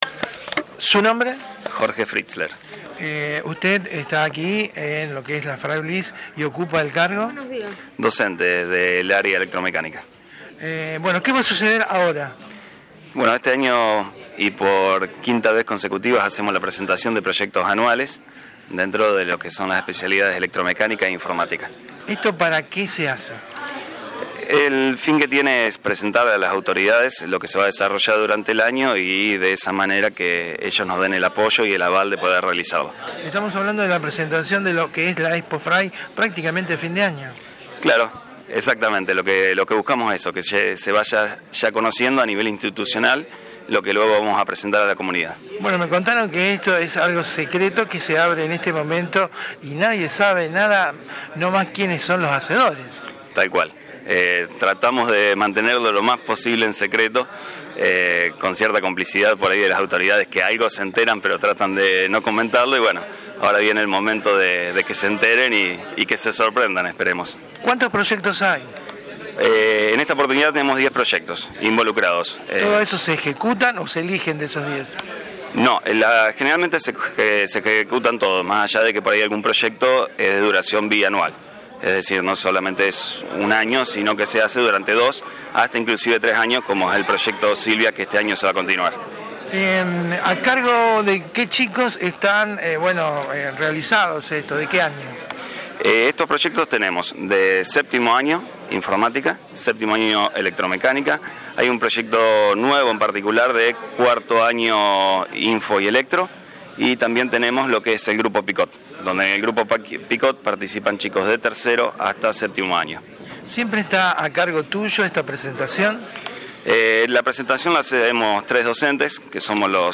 Integrante del cuerpo de profesores a cargo de los proyectoz